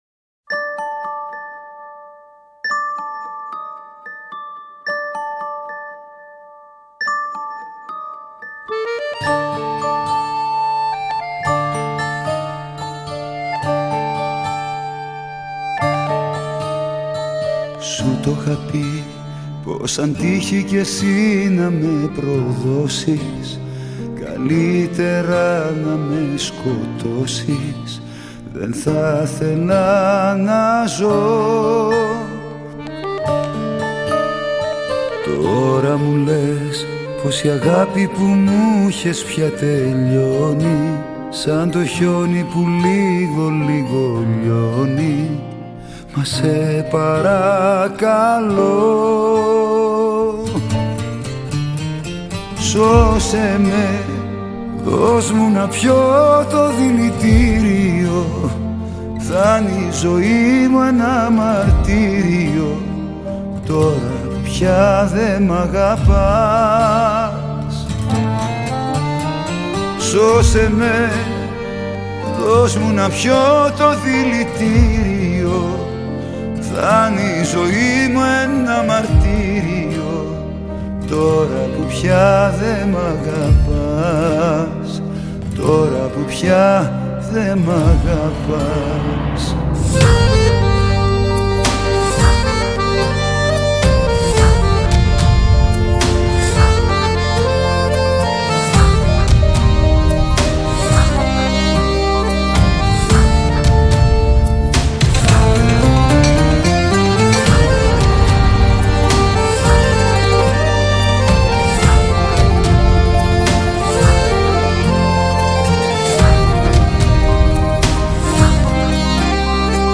Πρόκειται για ένα ιδιαίτερο και πολύ τρυφερό cd
13 διασκευές